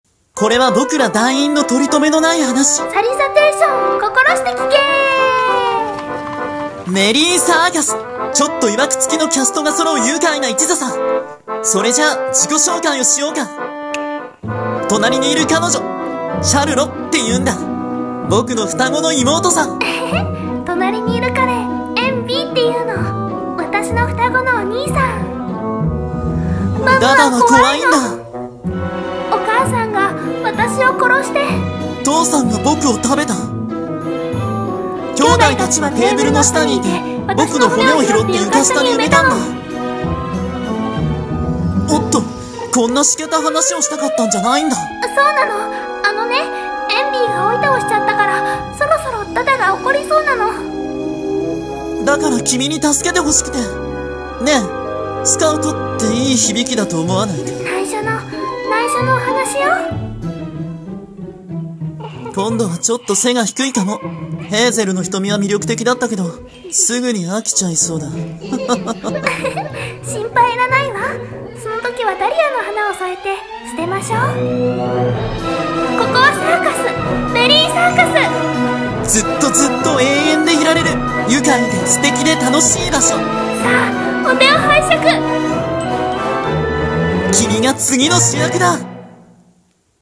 【コラボ用】CM風声劇「メリー・サーカス」